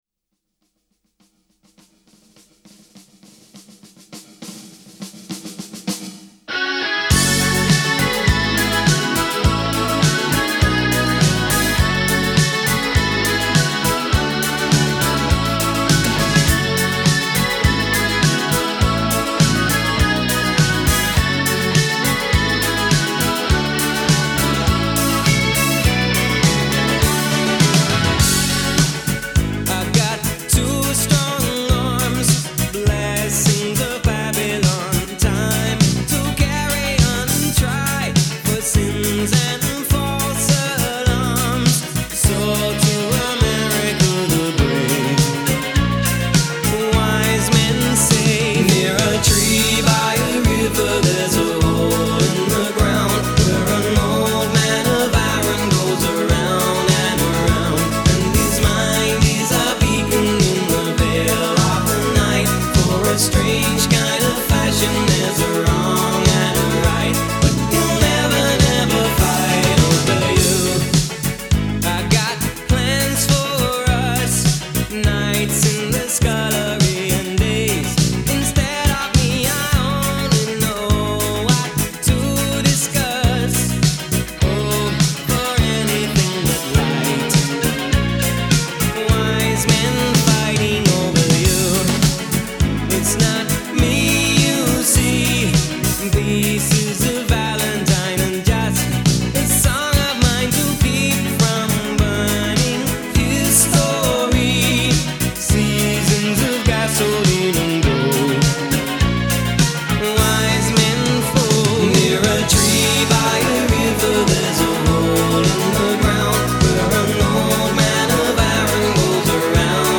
Жанр: Ретро, размер 6.16 Mb.